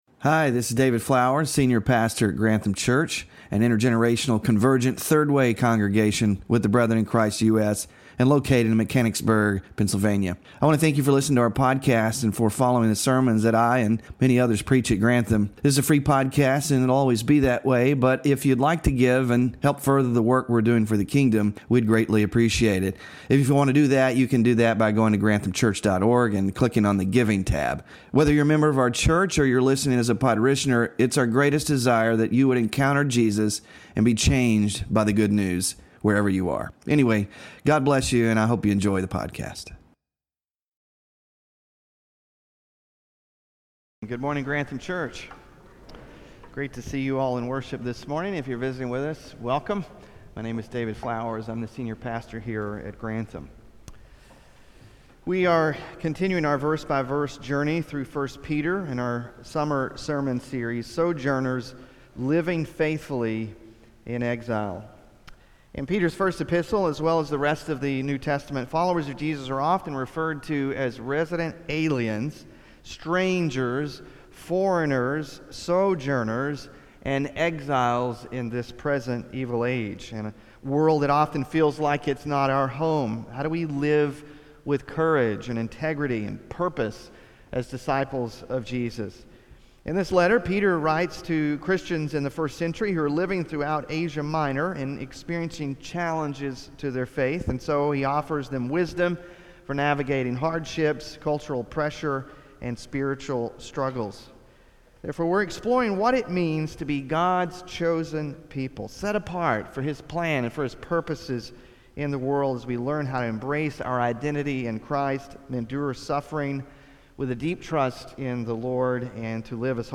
Sermon Focus: Peter urges believers to see themselves as sojourners and exiles who abstain from sinful desires and submit to authorities as a witness to God’s love and power.